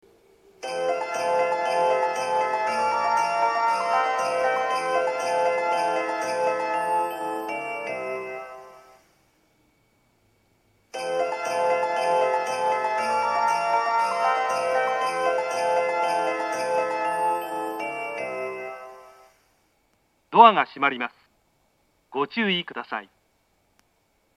３番線発車メロディー 曲は「煌く街並み」です。